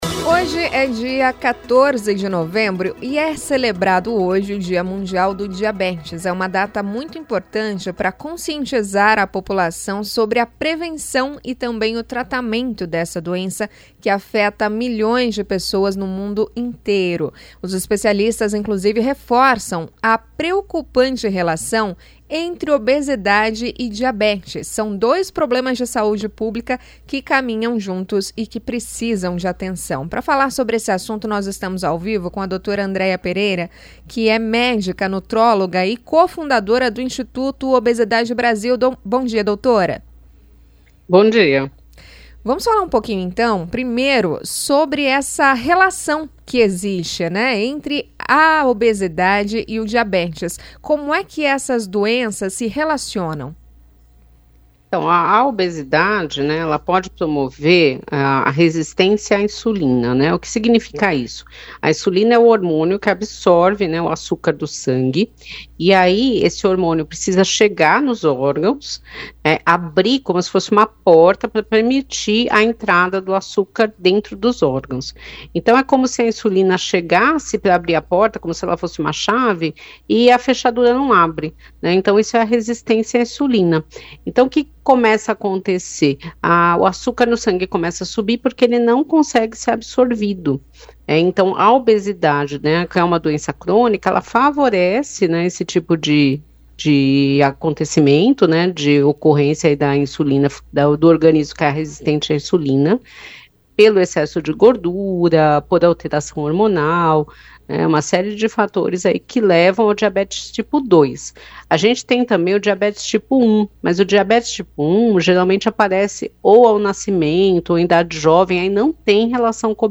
A médica nutróloga